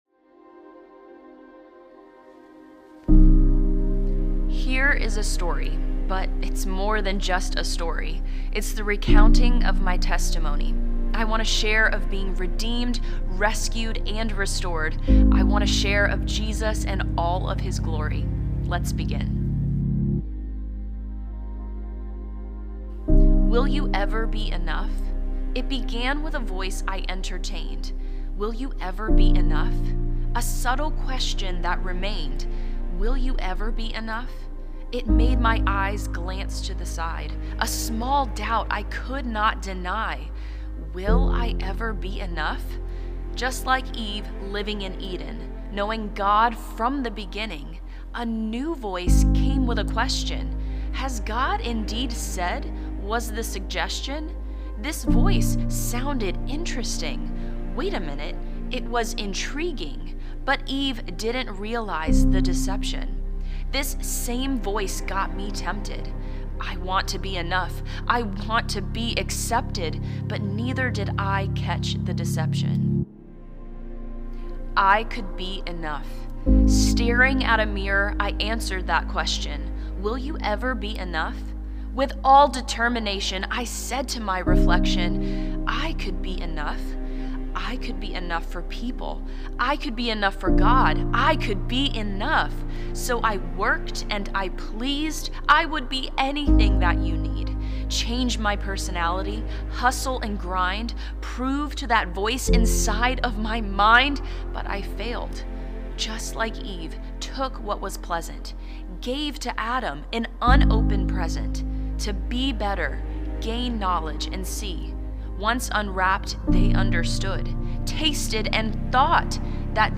Conference: Women's Gathering